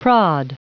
Prononciation du mot prod en anglais (fichier audio)
Prononciation du mot : prod